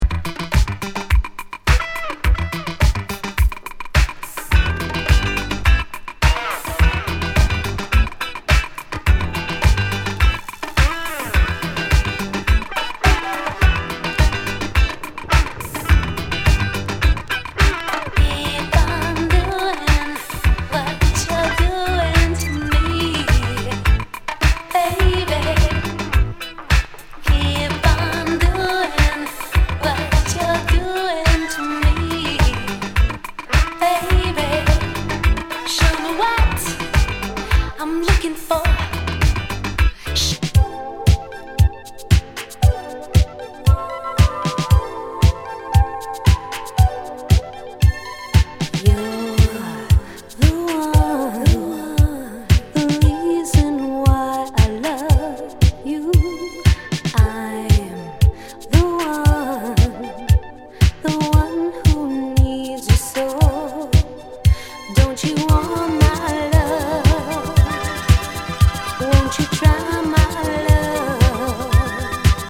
カナディアン・エレクトロ・ソウル/フリースタイル
ウィスパー・ディスコ